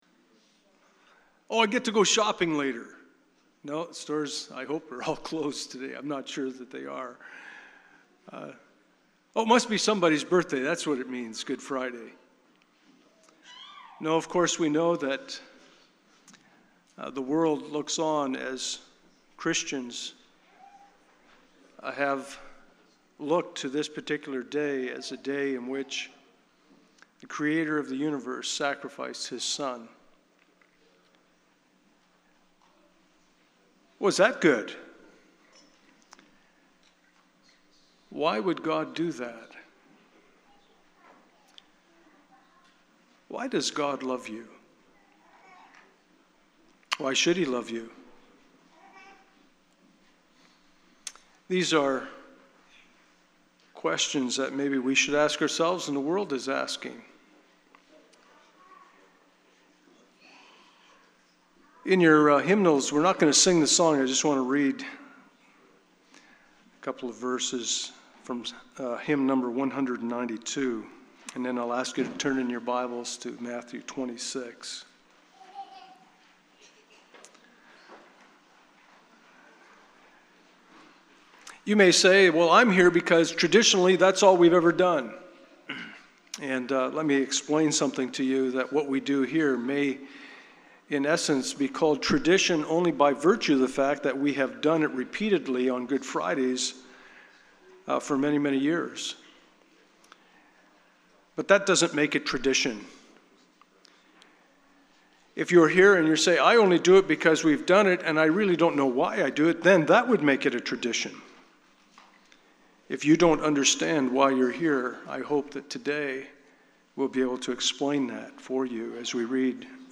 Good Friday Service – Communion